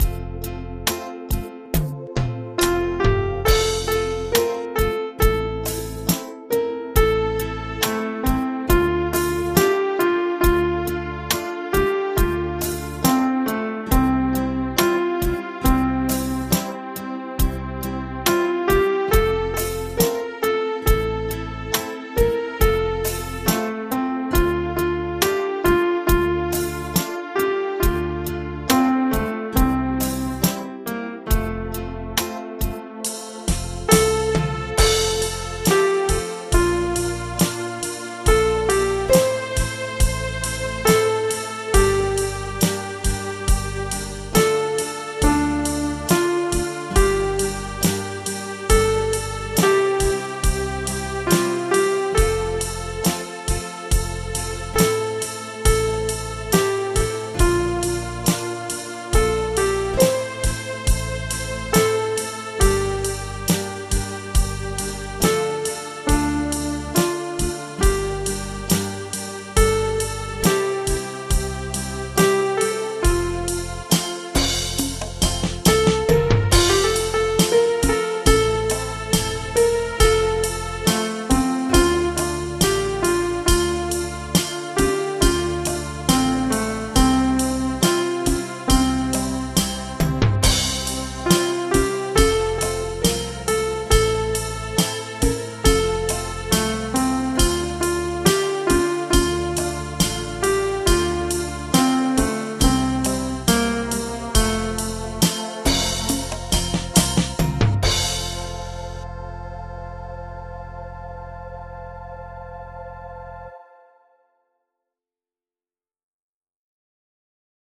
Audio Midi Bè 02: download